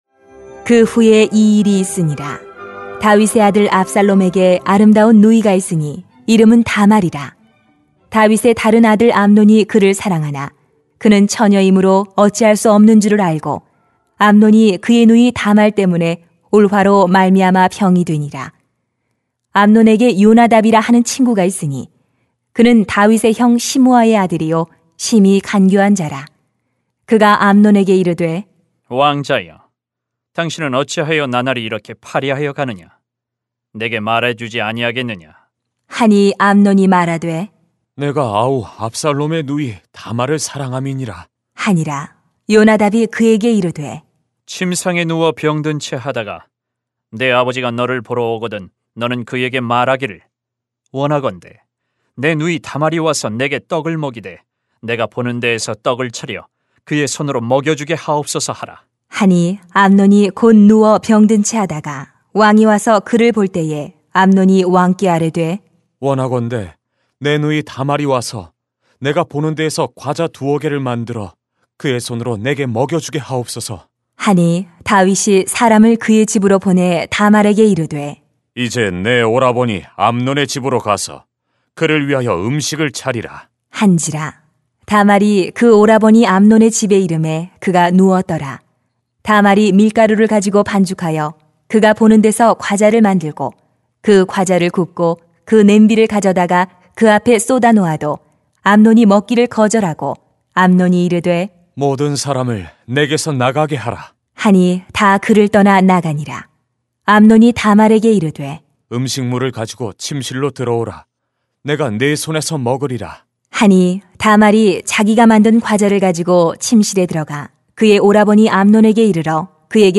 [삼하 13:1-22] 욕망에 메어 살지 마세요 > 새벽기도회 | 전주제자교회